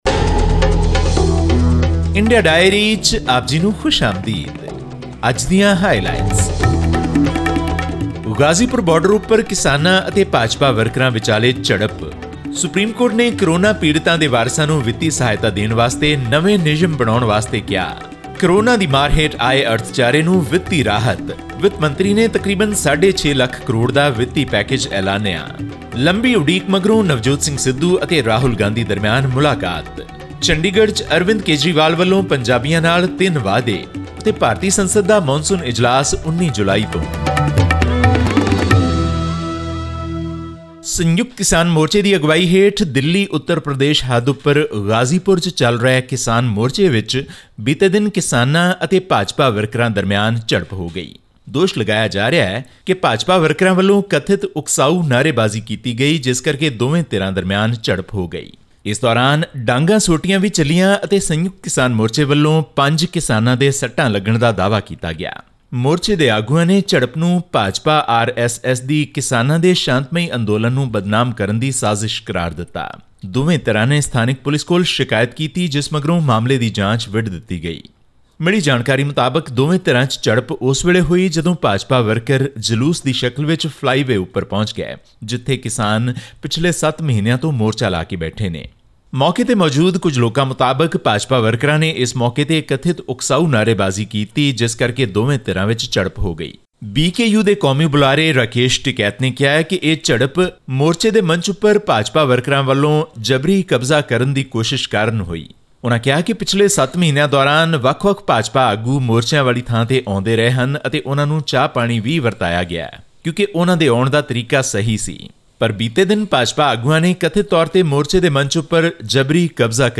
The National Disaster Management Authority (NDMA) has been given six weeks to frame guidelines for fixing ex gratia meant for the families of those who died because of the pandemic. All this and more in our weekly news segment from India.